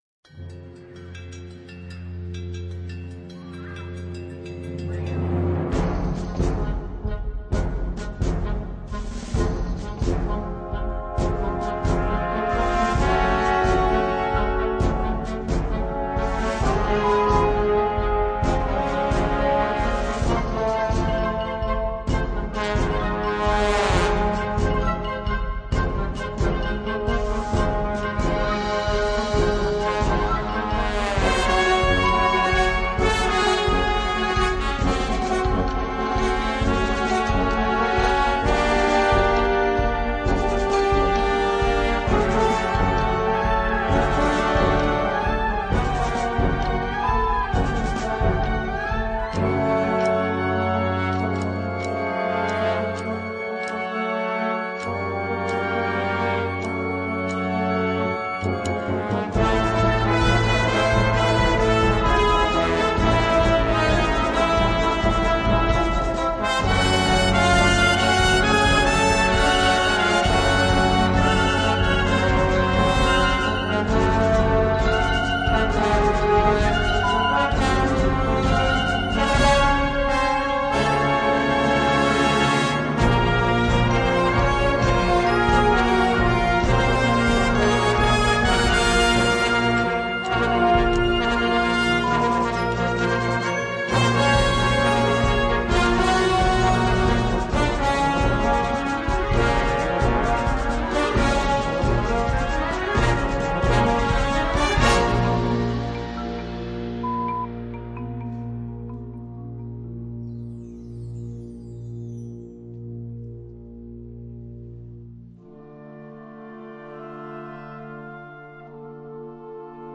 Gattung: Filmmusik
Besetzung: Blasorchester
starken Medley für Blasorchester